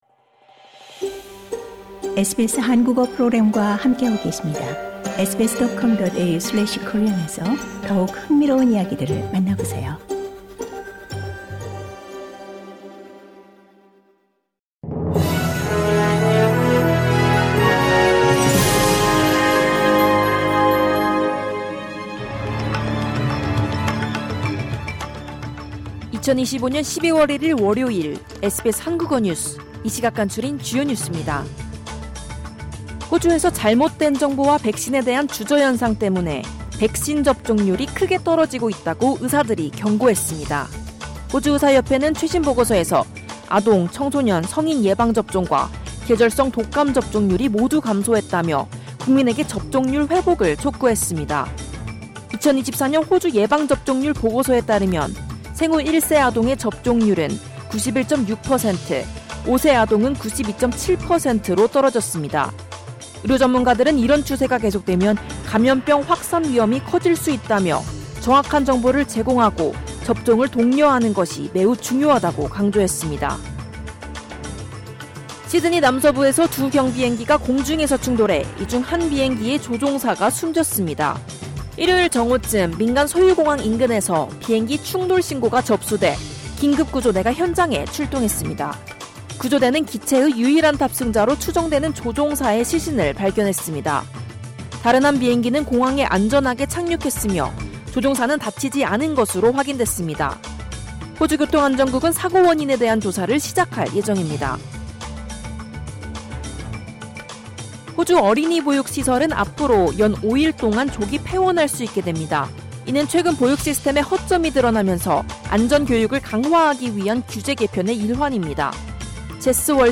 호주 뉴스 3분 브리핑: 2025년 12월 1일 월요일